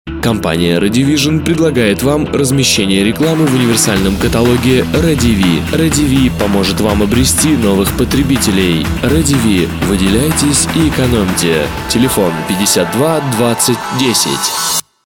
Инфоролик